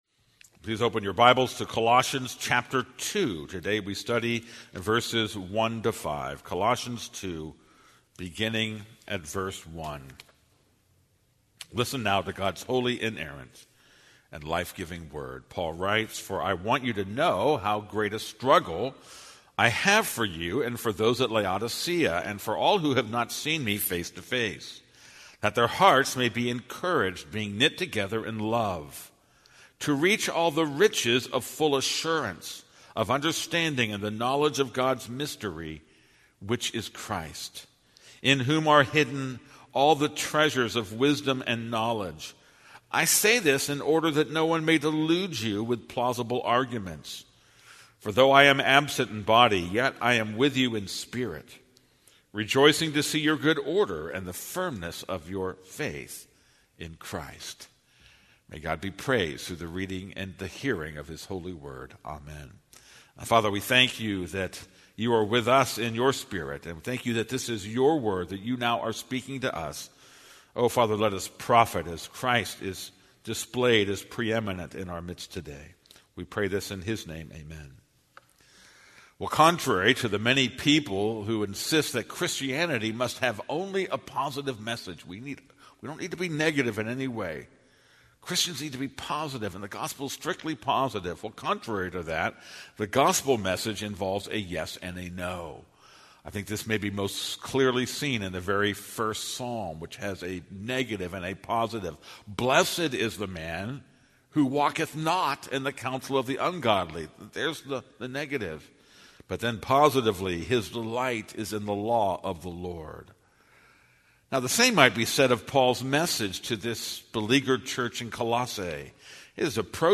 This is a sermon on Colossians 2:1-5.